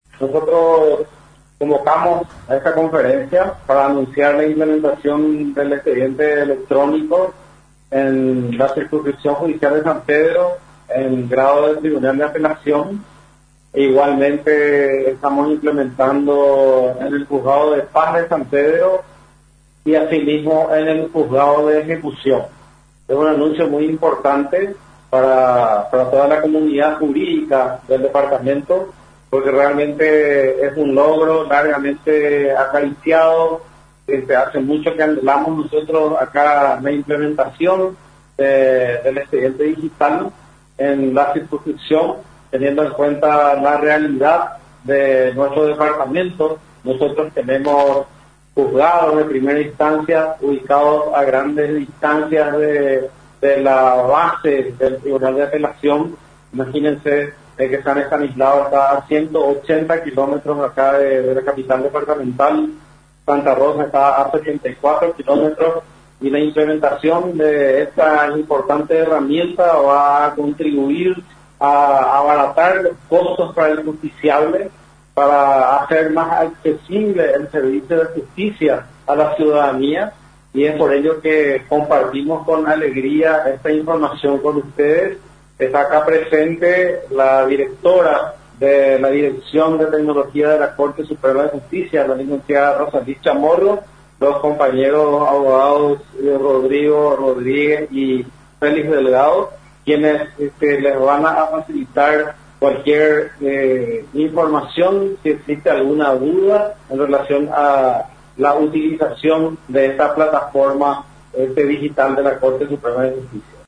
Durante una conferencia de prensa realizada en la fecha, indicaron que la implementación se enmarca en un plan de la Dirección General de Tecnología de la Información y las Comunicaciones, para el uso de esta herramienta digital en todas las circunscripciones judiciales del país.
Nota: Dr. Fernando Benítez-Presidente de la Circunscripción Judicial de San Pedro